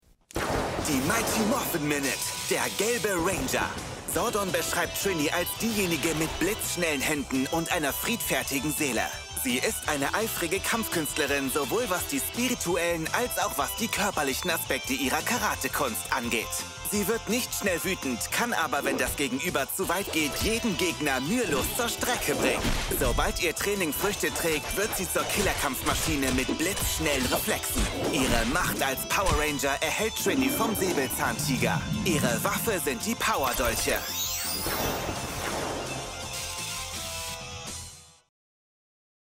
Male
Yng Adult (18-29), Adult (30-50)
Soundproof recording booth: 38 dB in the midrange and up to 84 dB in the high-frequency range
Microphone: Neumann TLM 103
Power Rangers Revoiced Dub